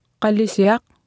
Below you can try out the text-to-speech system Martha.
Speech synthesis Martha to computer or mobile phone